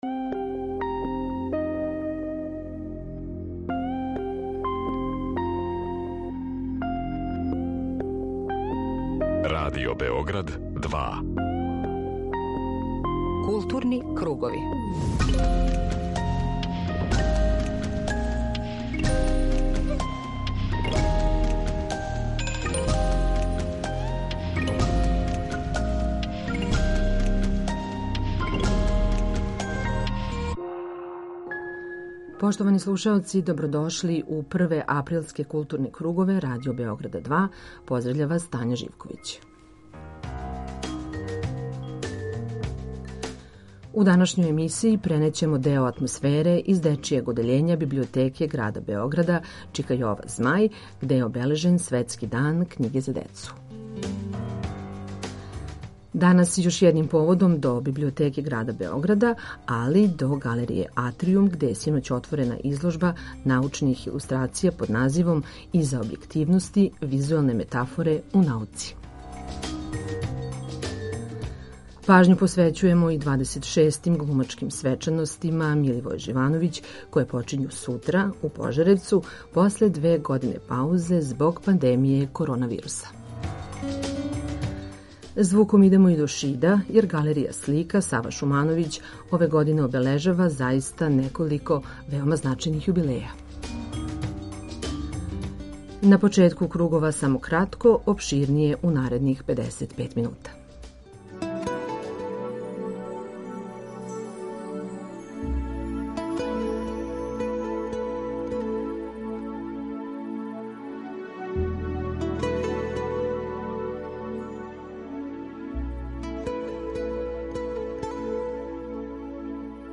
У Културним круговима пренећемо атмосферу из Дечјег одељење Библиотеке града Београда ,,Чика Јова Змај", где је данас обележен Светски дан књиге за децу.